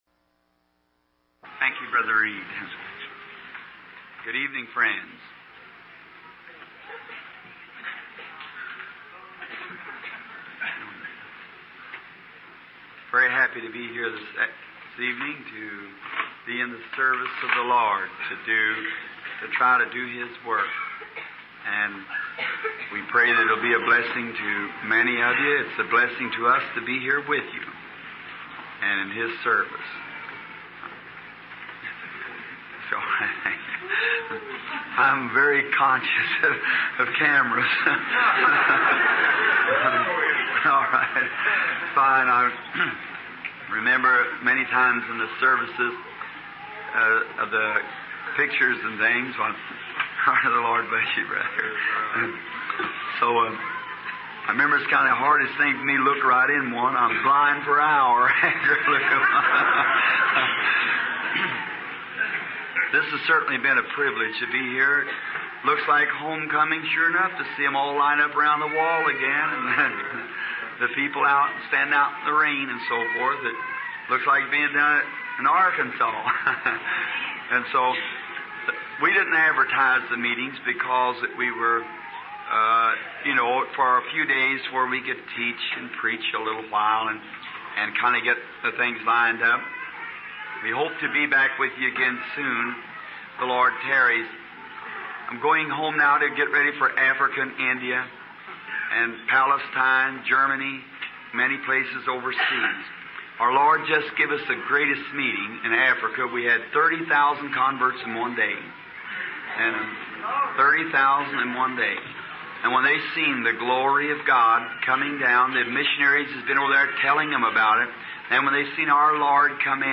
aufgezeichneten Predigten